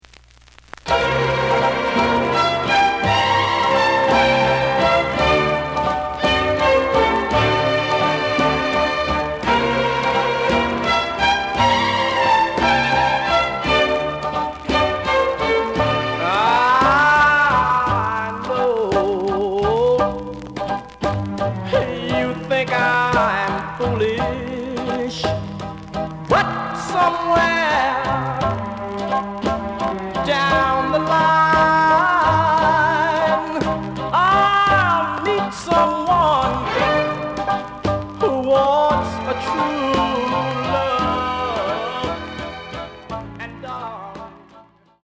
●Genre: Rhythm And Blues / Rock 'n' Roll